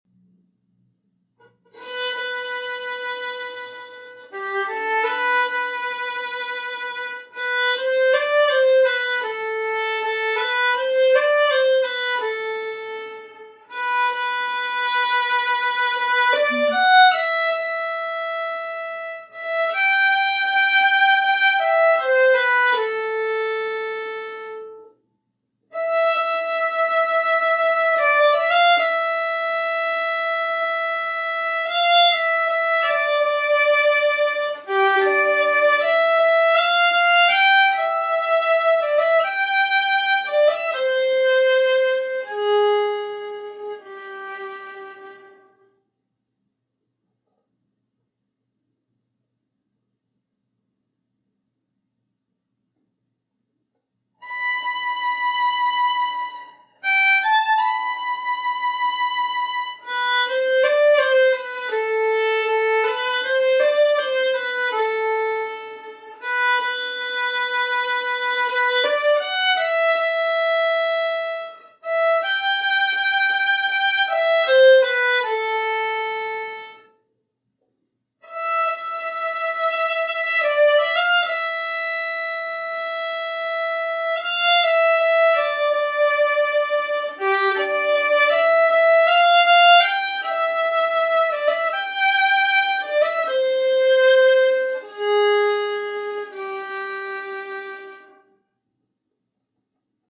Easy Violin Sheet Music
• violin solo